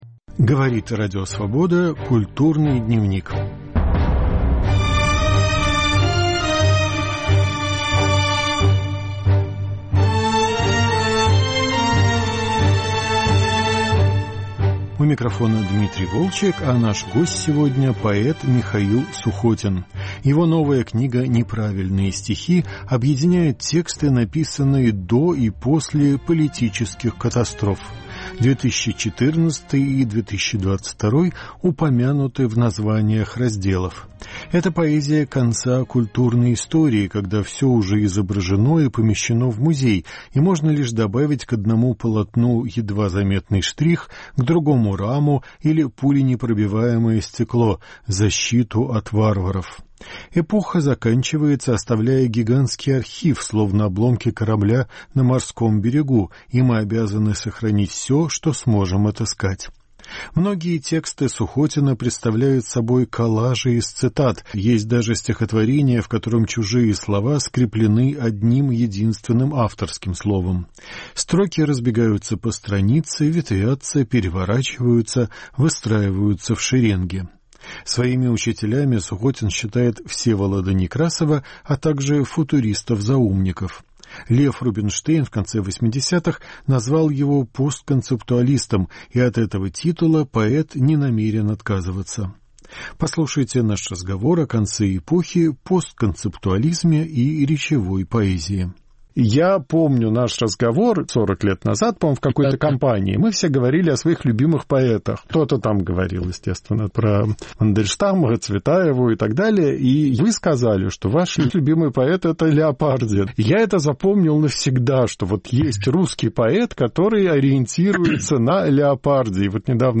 Разговор о новой книге "Неправильные стихи"